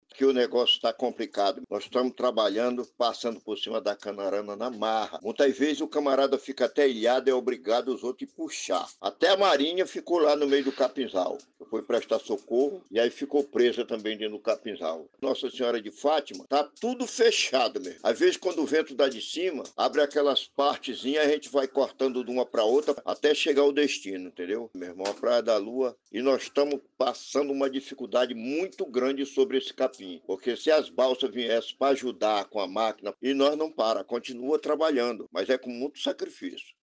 SONORA_BARQUEIRO-.mp3